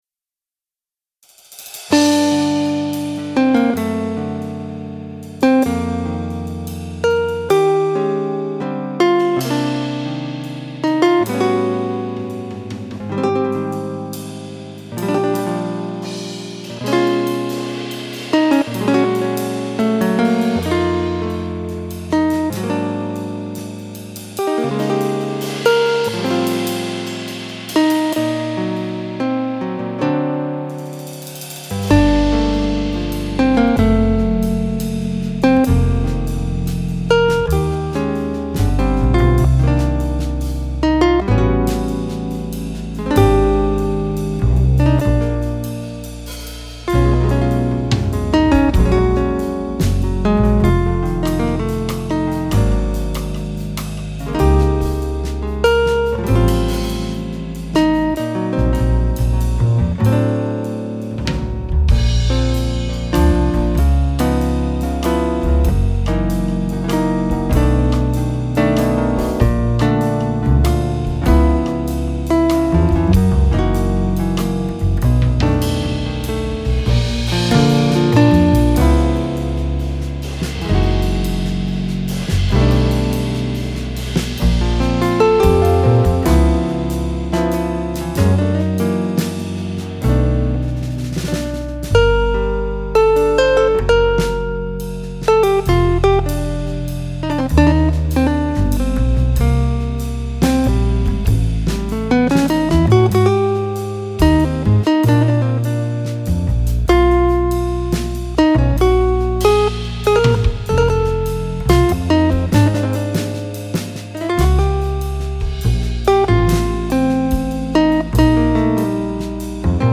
• software synthesizer
• type: physical modeling (snaren)